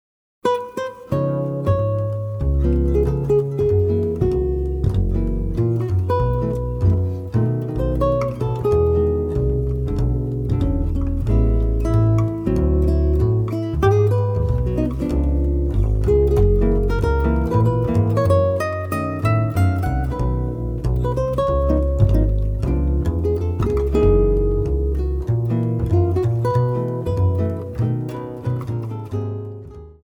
seven-string acoustic guitar